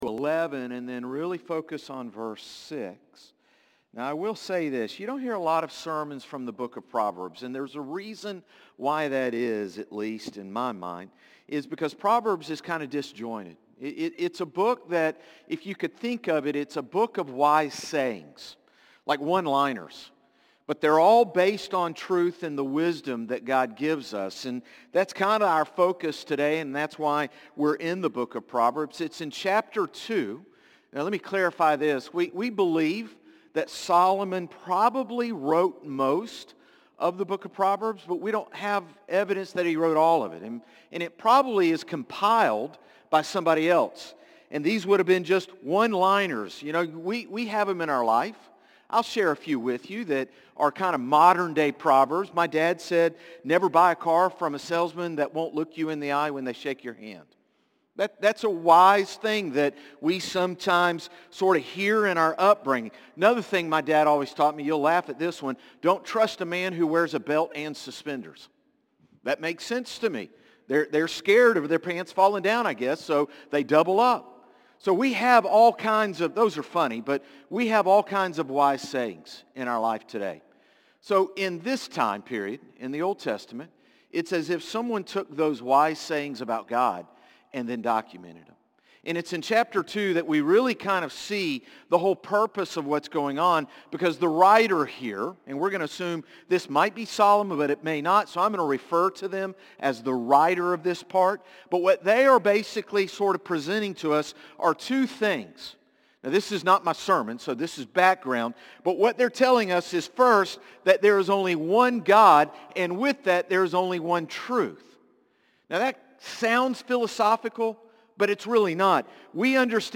Sermons - Concord Baptist Church
Morning-Service-4-27-25.mp3